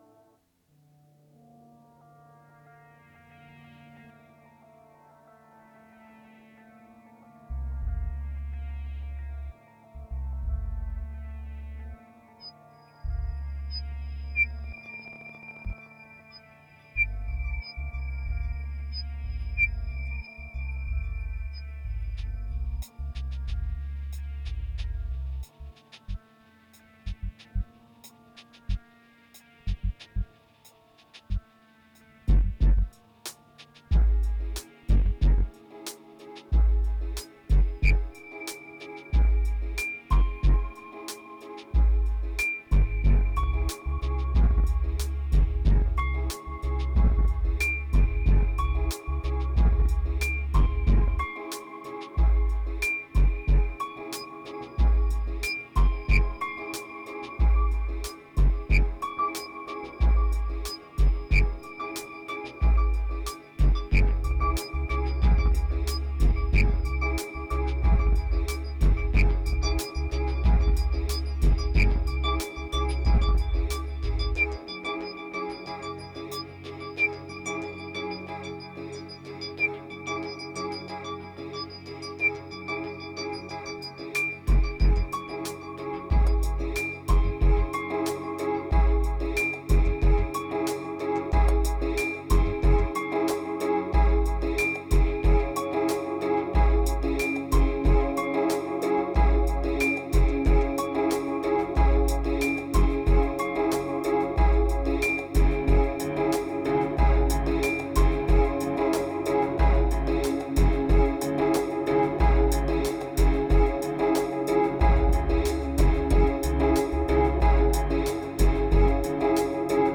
2350📈 - 70%🤔 - 92BPM🔊 - 2017-06-05📅 - 525🌟